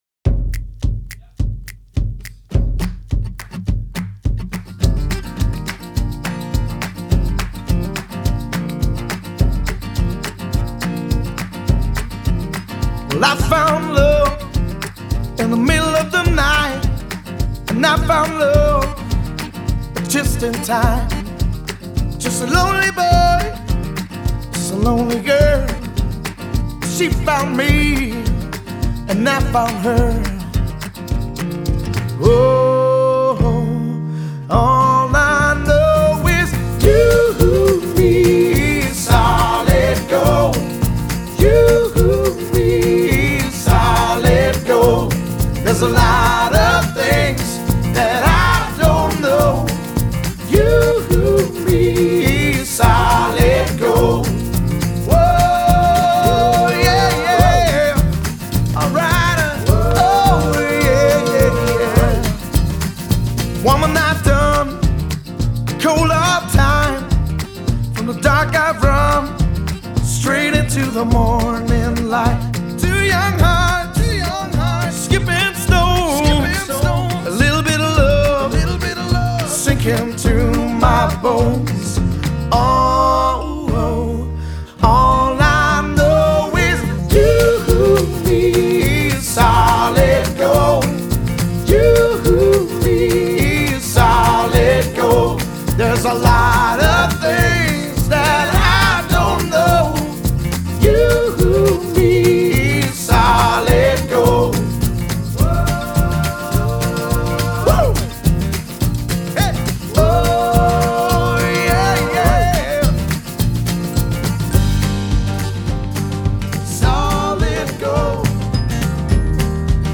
Genre: Pop, Rock, Blues